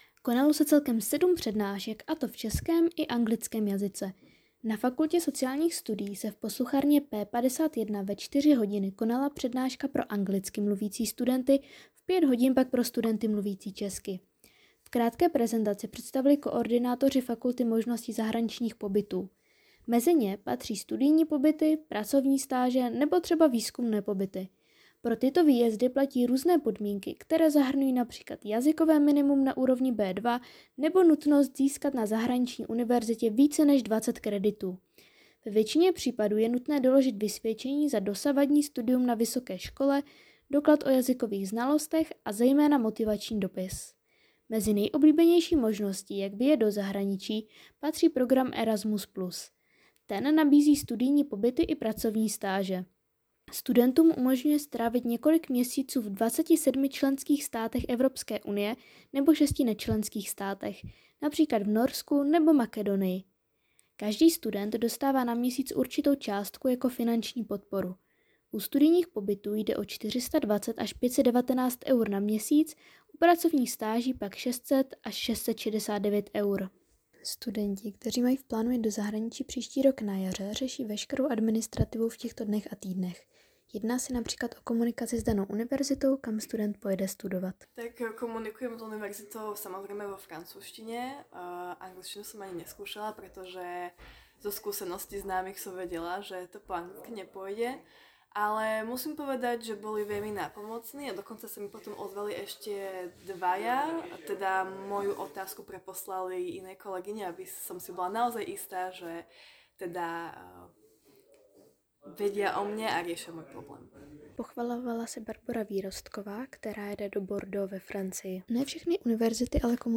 Reportáž Erasmus Days 2021.wav